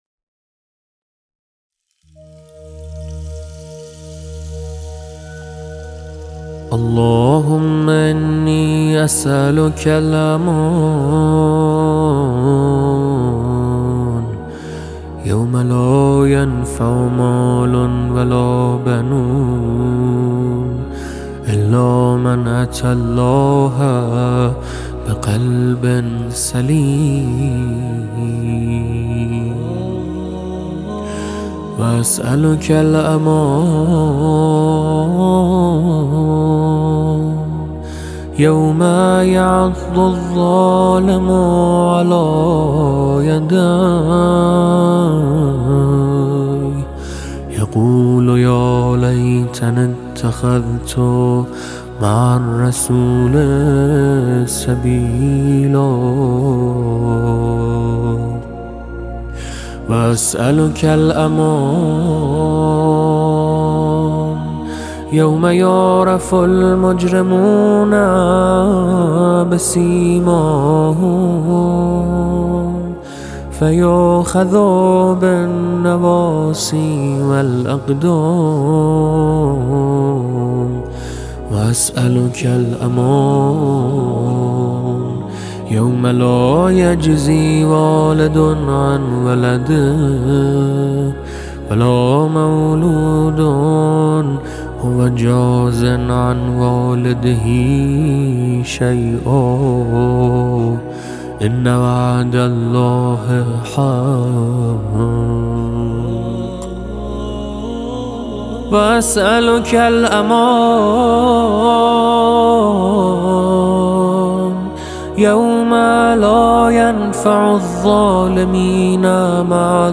ادعیه